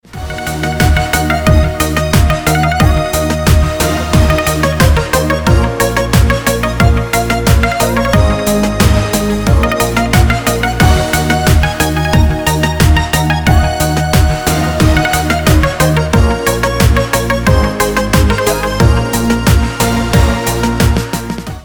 • Песня: Рингтон, нарезка
Рингтон без слов на звонок